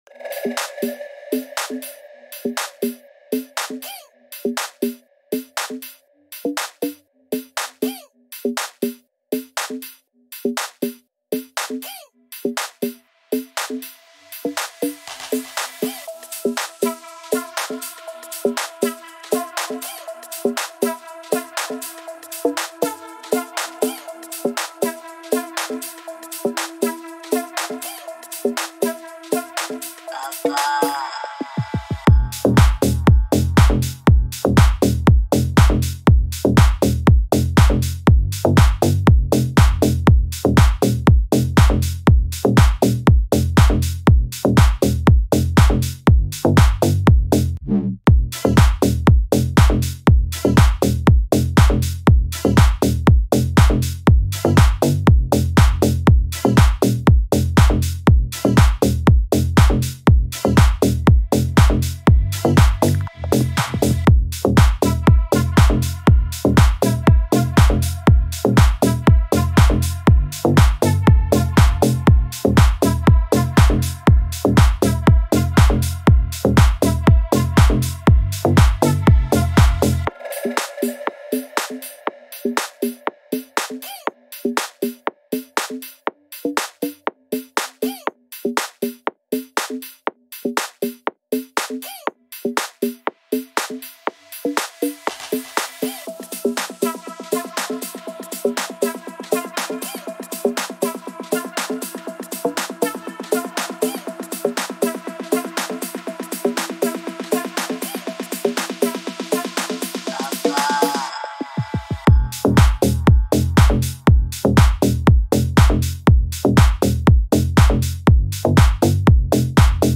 Ударные и главное бас!